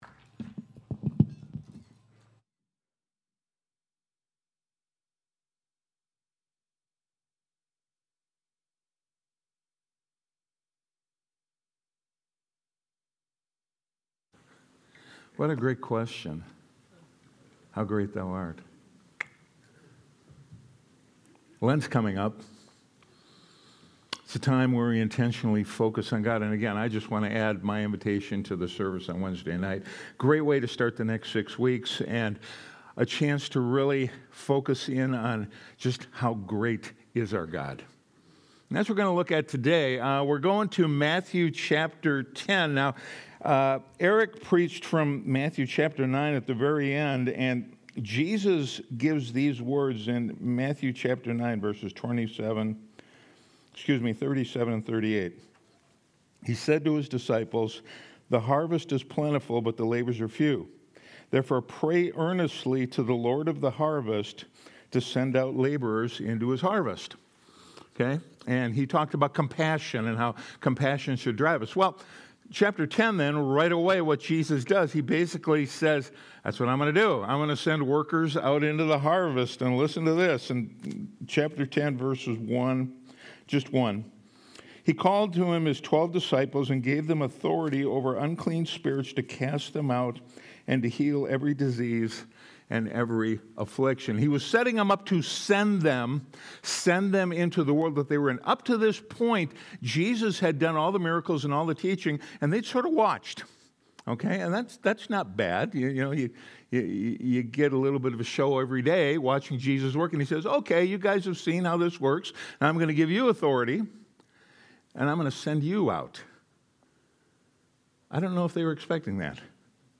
Sunday Sermon: 2-15-26